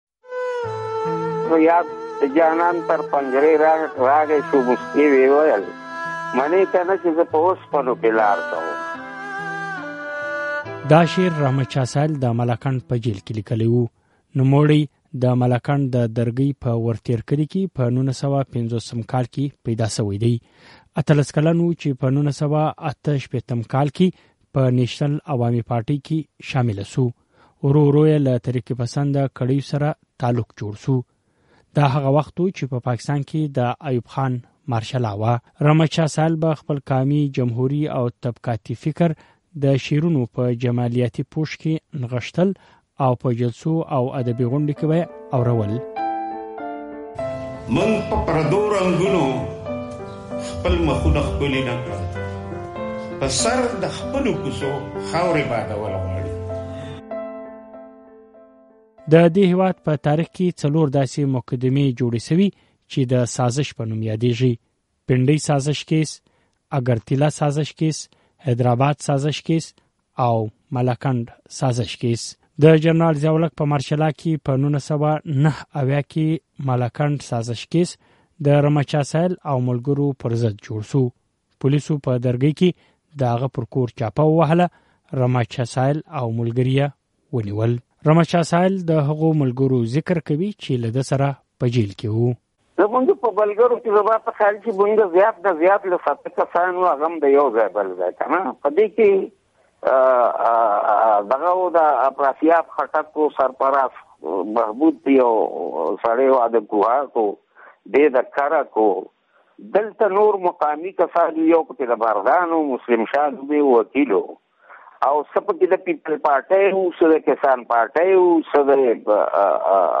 جمهوري غږونه د مشال راډیو نوې خپرونه ده چې پکې له هغو سیاستوالانو، خبریالانو، پوهانو او فعالانو سره مرکې کېږي چې په پاکستان کې یې د ولسواکۍ او د حقونو لپاره د مبارزې پر مهال زندانونه تېر کړي، مشکلات یې ګاللي او ځورېدلي دي.